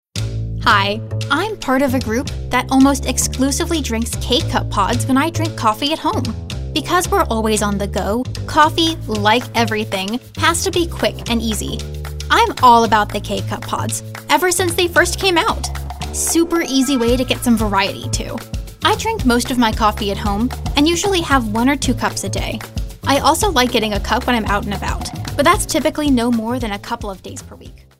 anti-announcer, confident, conversational, cool, friendly, genuine, girl-next-door, real, sincere, storyteller, upbeat, young, young adult